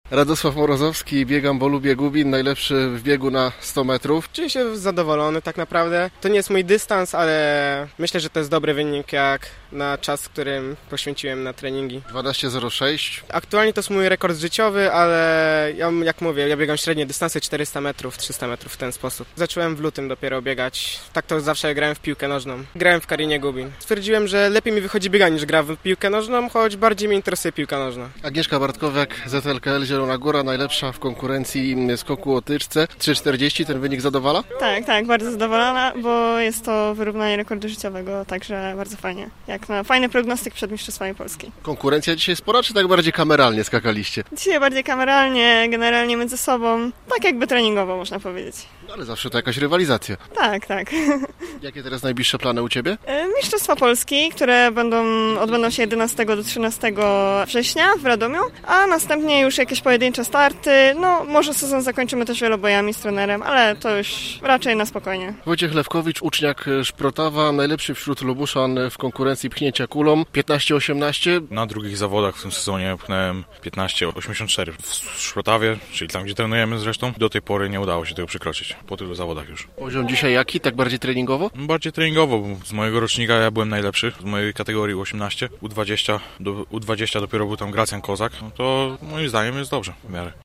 Po ukończeniu zmagań rozmawialiśmy z najlepszymi Lubuszanami w swoich konkurencjach: